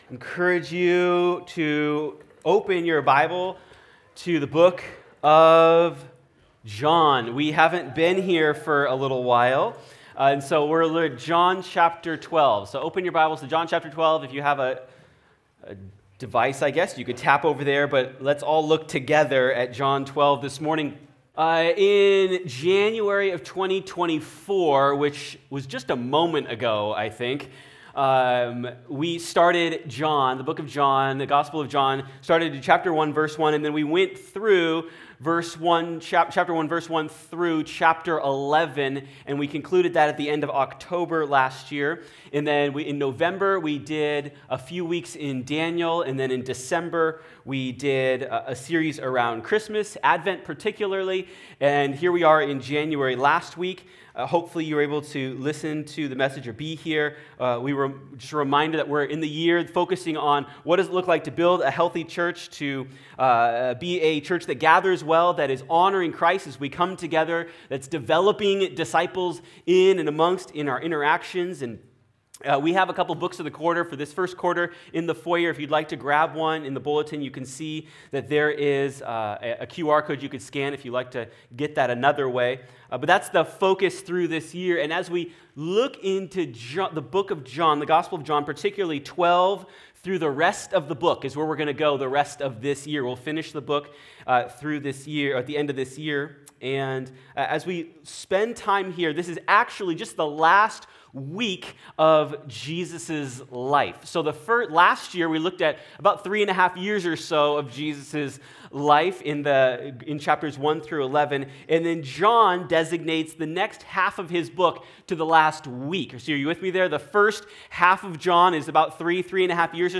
Shortly after Jesus raised Lazarus from the dead, a dinner party was hosted in response to this wonderful miracle! In this passage, we encounter five people, who represent five groups and how they respond to Jesus. Sermon